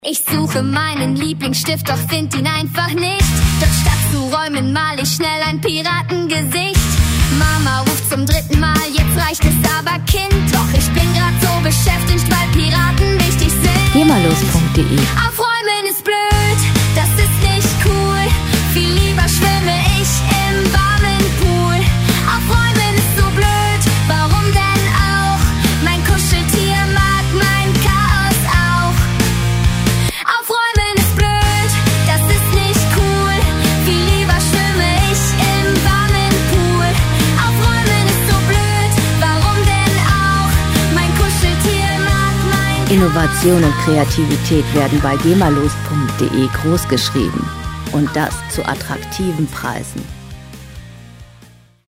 • Teenie Rockband